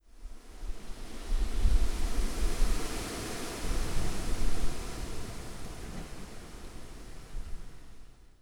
Gust 4.wav